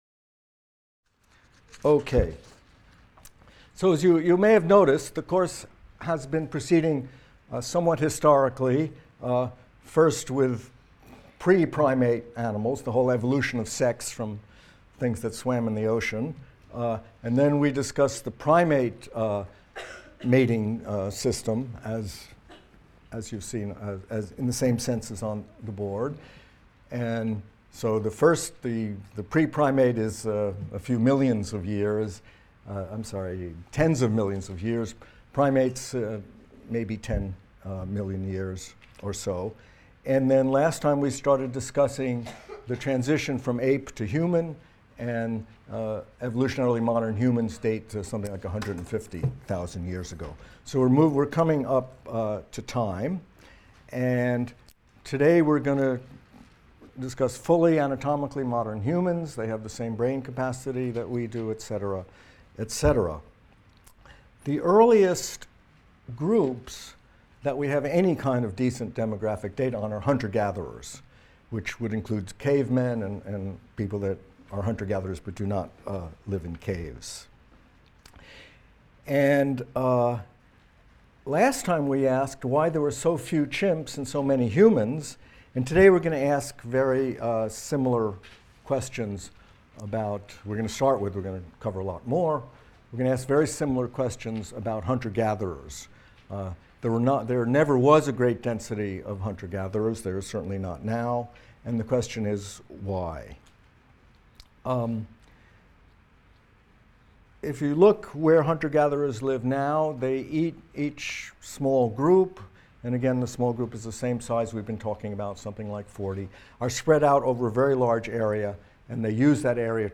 MCDB 150 - Lecture 4 - When Humans Were Scarce | Open Yale Courses